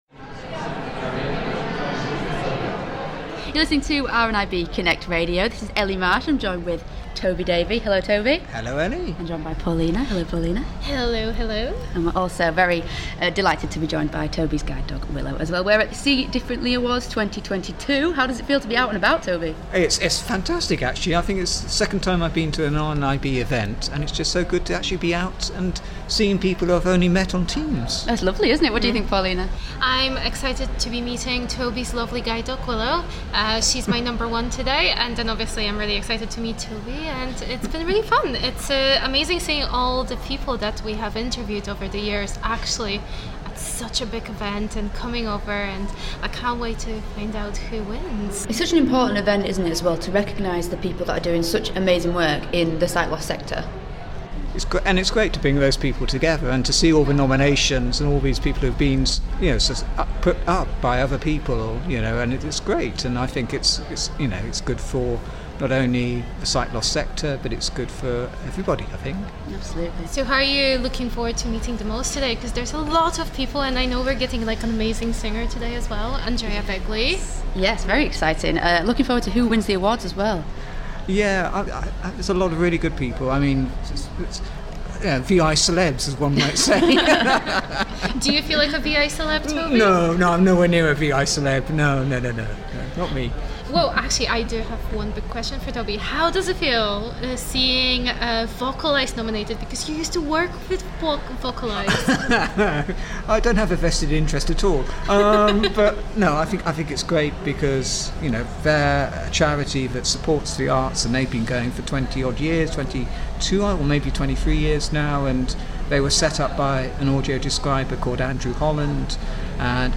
Last night in central London a very special award ceremony took place, the RNIB See Differently Awards